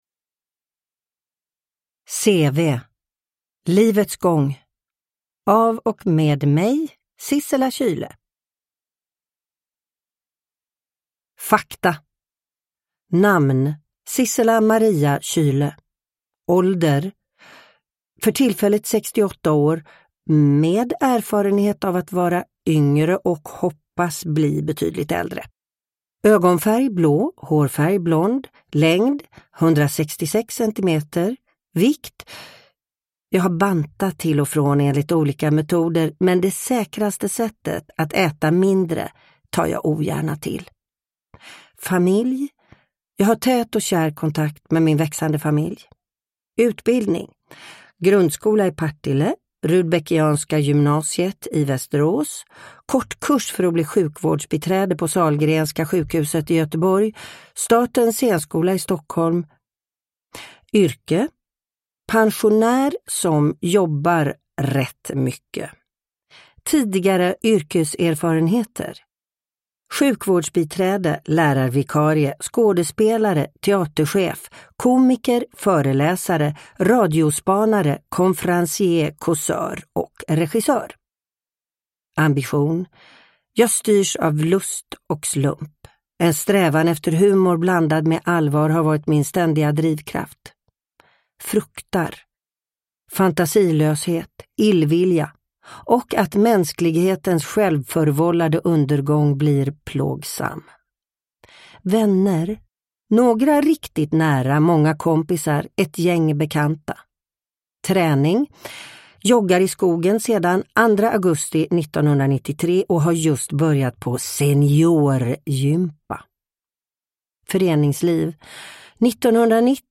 CV. Livets gång – Ljudbok
Uppläsare: Sissela Kyle